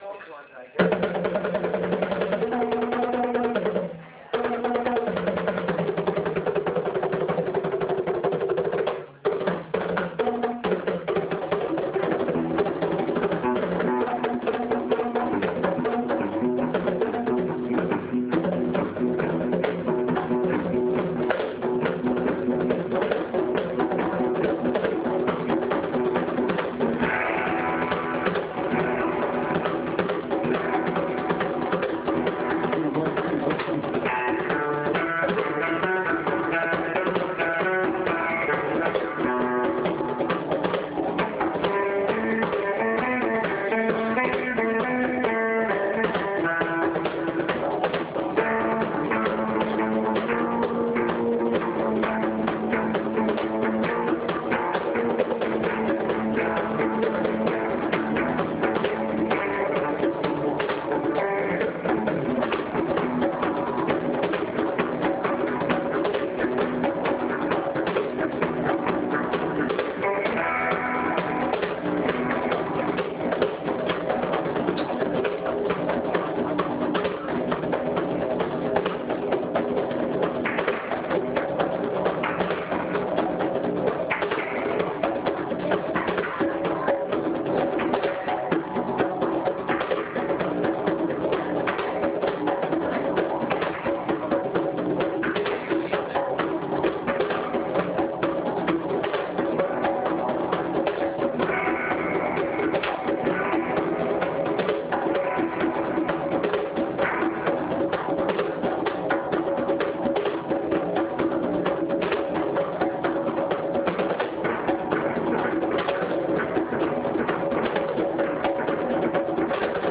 Falls Creek Surf Music.wav
Falls Creek surf music.wav